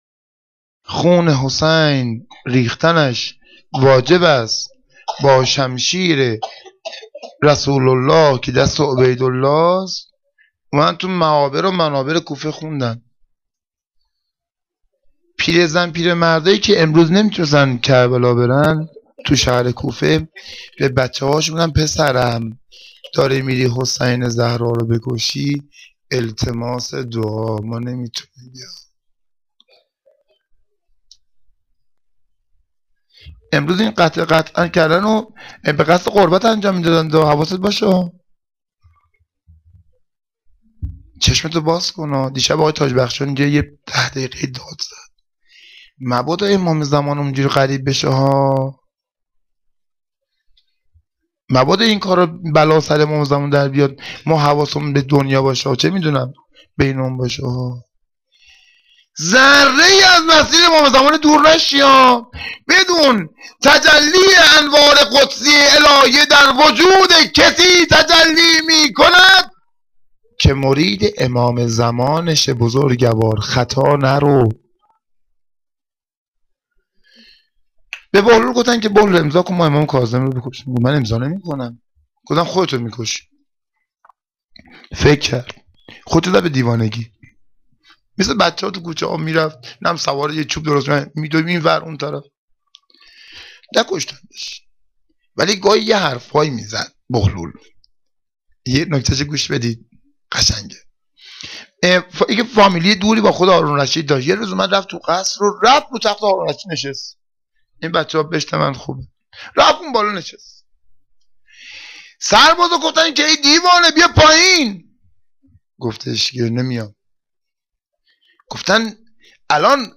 سخنرانی-11.2.wma